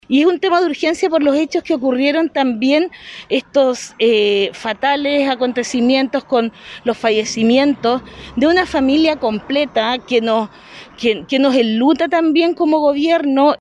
La delegada presidencial de Los Ríos, Paola Peña, ratificó el plazo dicho por la alcaldesa, confirmando que para el Gobierno es un tema urgente debido a la gran cantidad de personas que fallecen todos los años.